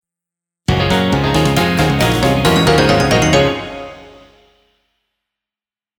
みじかめサウンドなシリーズです
ゲームサウンドやアイキャッチを意識した